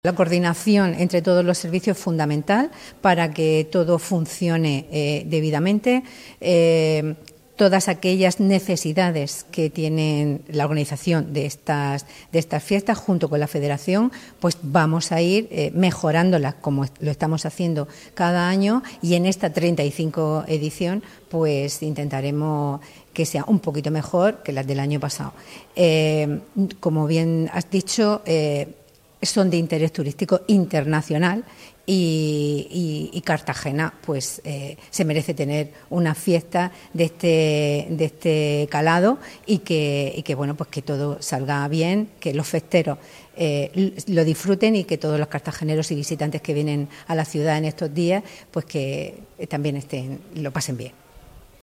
Enlace a Declaraciones de la concejala de Festejos, Francisca Martínez